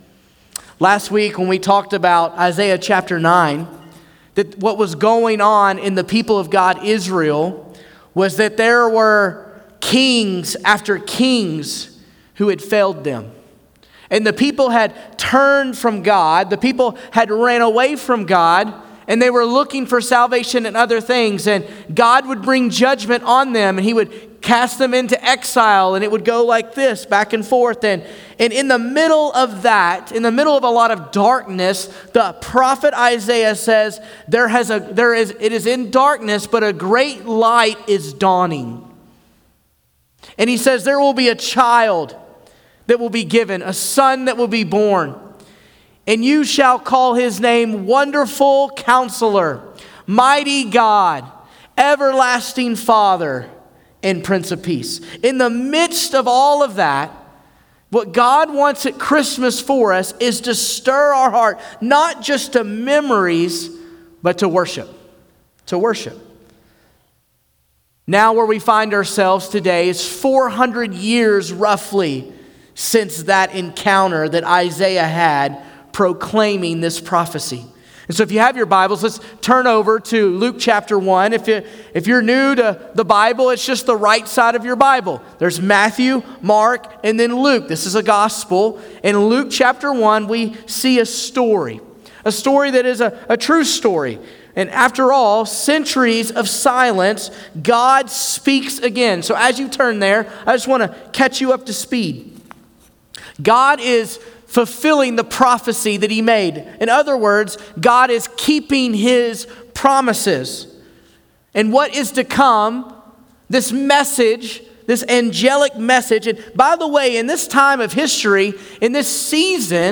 December15sermon.mp3